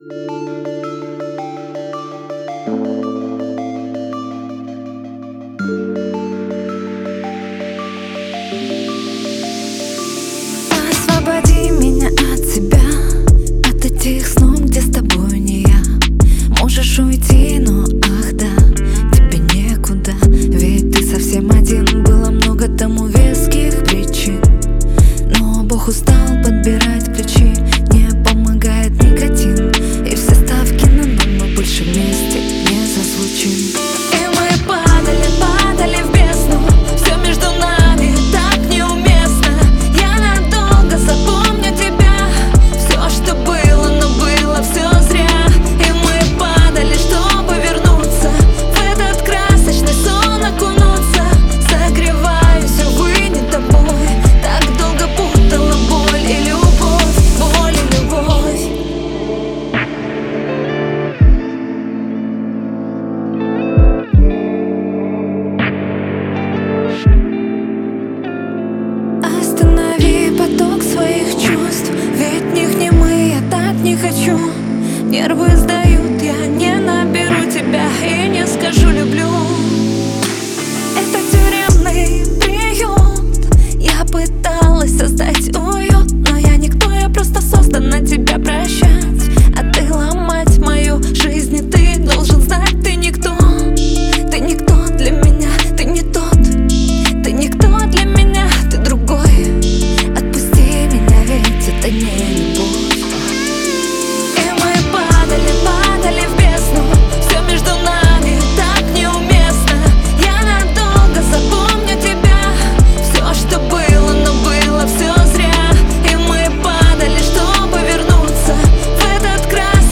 это эмоциональный трек в жанре поп-рок